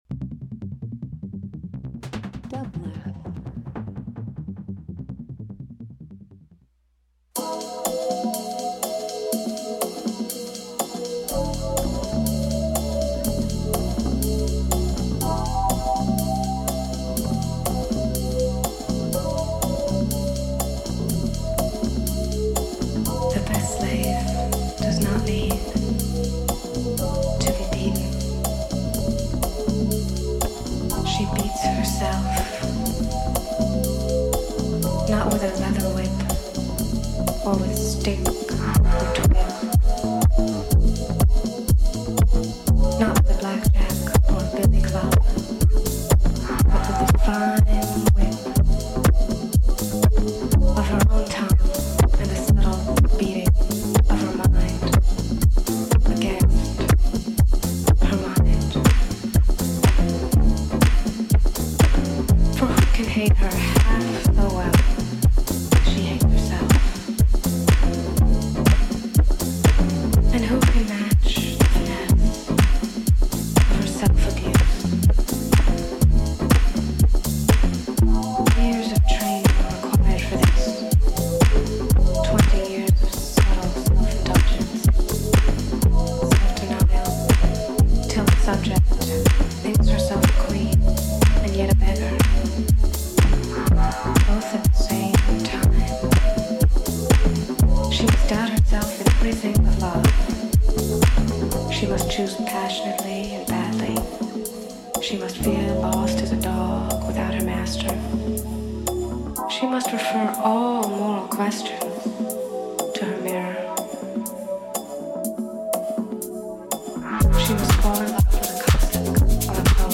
Alternative Dance Electronic Live Performance
bass